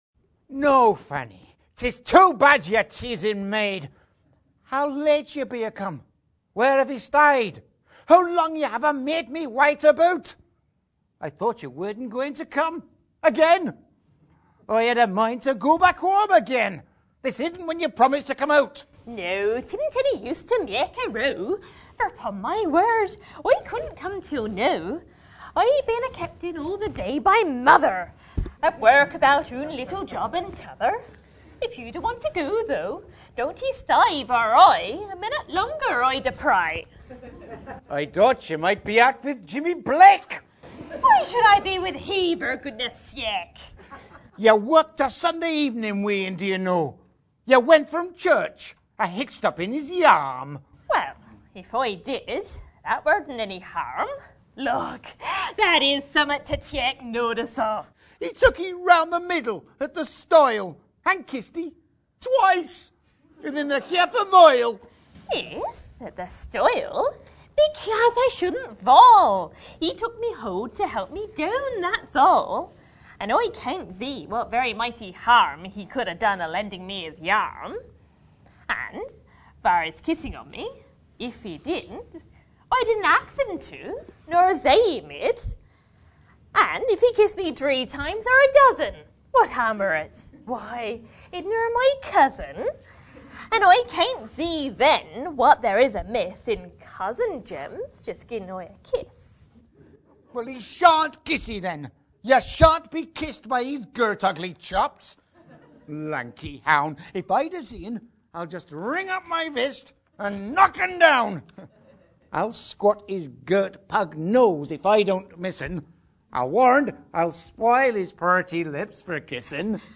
Live Recordings (Six Eclogues)
from William Barnes's Poems of Rural Life in the Dorset Dialect (First Collection, 1844)
Recorded Live at the 2010 Adelaide Fringe
Live audio recordings